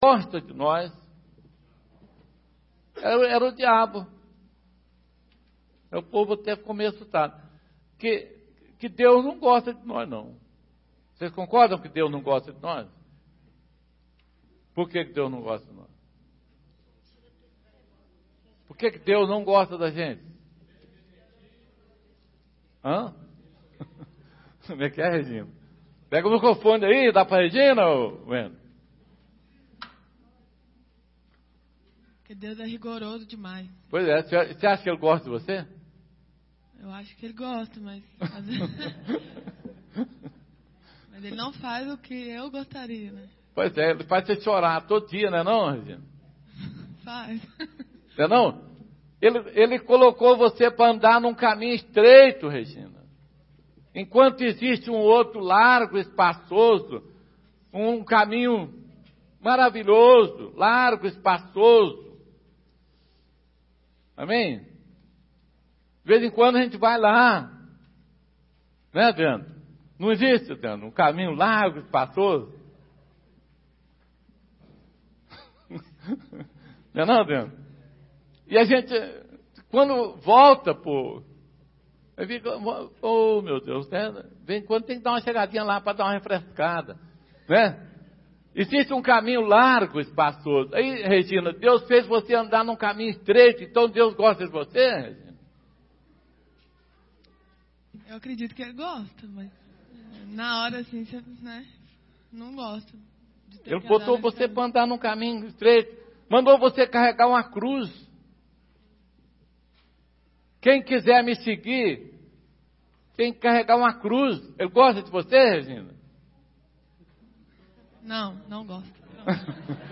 Queda de energia – gravou apenas uma parte.